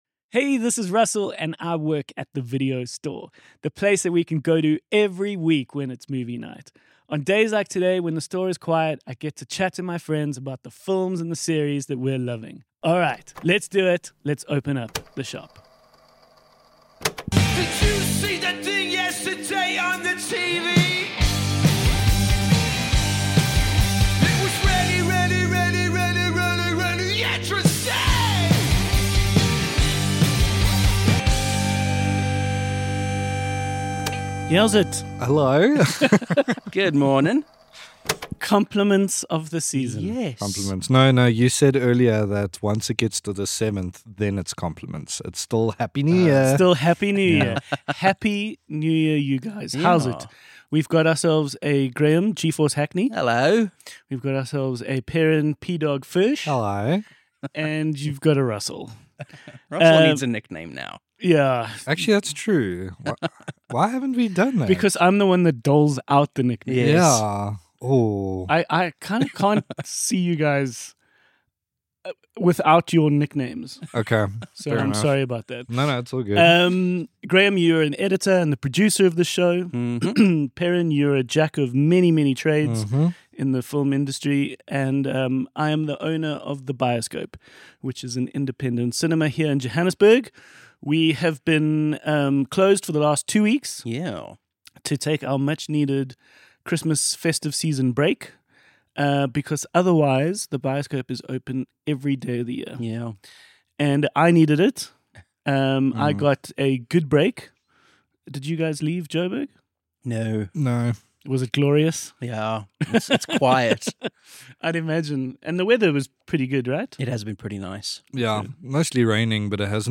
A weekly chat amongst friends working a shift at your local video store.